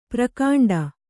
♪ prakāṇḍa